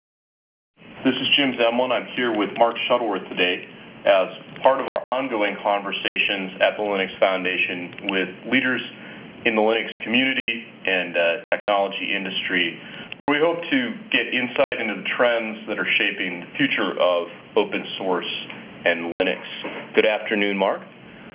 As reference speech fragment the part of Mark Shuttleworth's interview was given.
Then we pass this speech sample through wav2rtp with filter "independent packet losses" turned on and compare source and degraded file with pesqmain utility.
Table 1: Independent network losses influation on the output speech quality (G.729u)